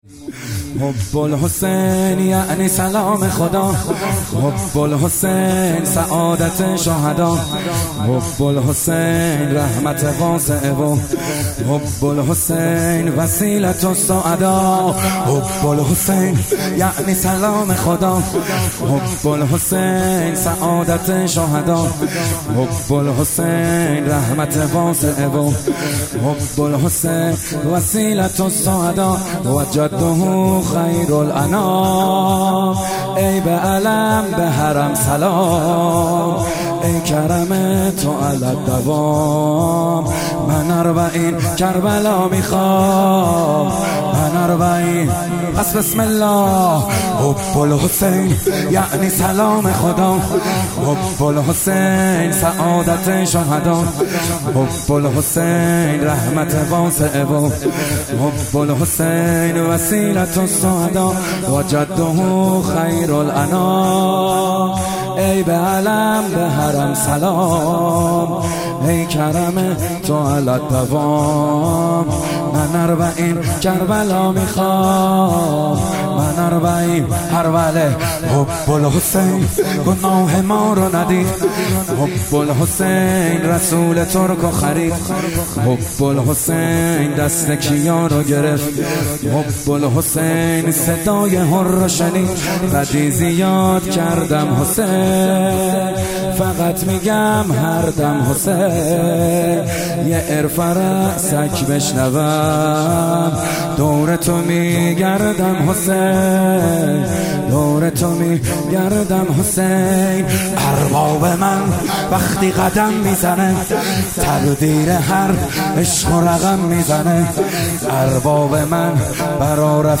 محرم 98 روز پنجم - شور - حب الحسین یعنی سلام خدا